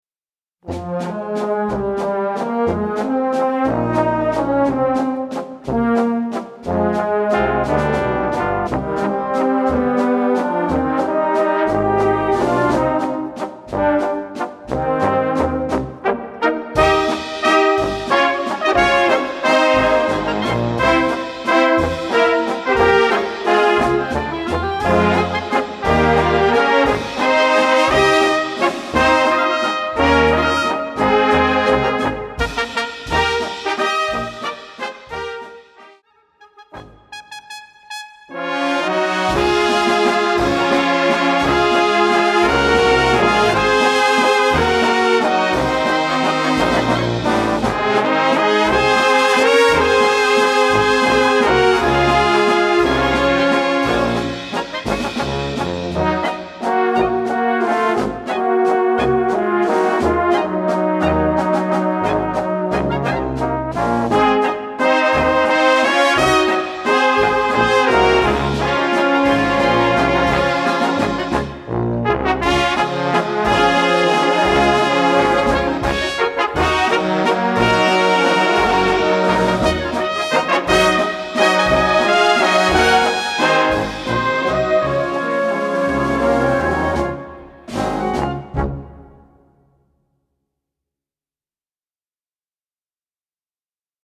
Physisch und digital für Blasorchester erhältlich.